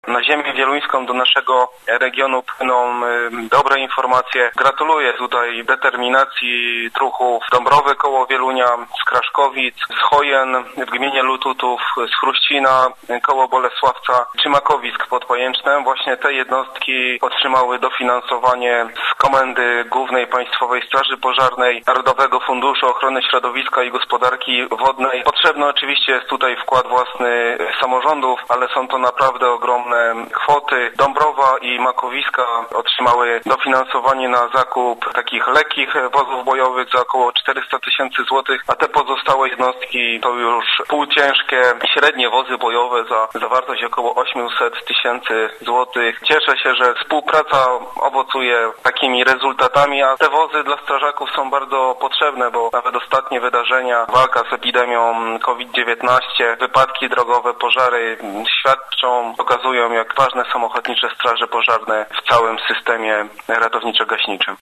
Na ziemię wieluńską, do naszego regionu płyną dobre informacje – mówi Paweł Rychlik, poseł na Sejm RP.